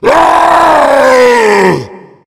burer_attacking_0.ogg